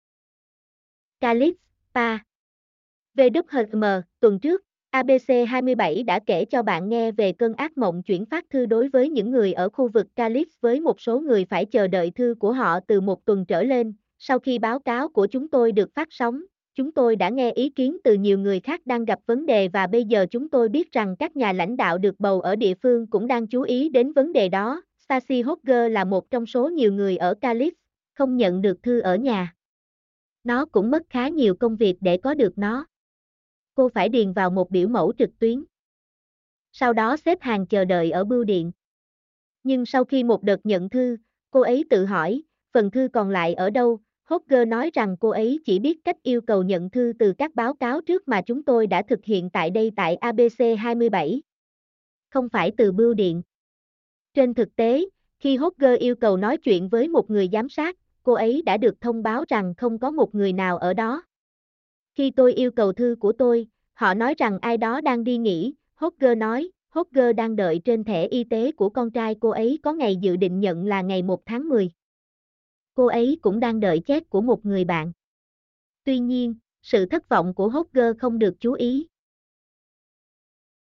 mp3-output-ttsfreedotcom-9.mp3